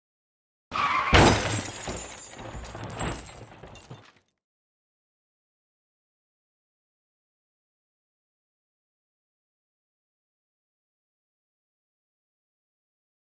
دانلود آهنگ تصادف ماشین 2 از افکت صوتی حمل و نقل
دانلود صدای تصادف ماشین 2 از ساعد نیوز با لینک مستقیم و کیفیت بالا
جلوه های صوتی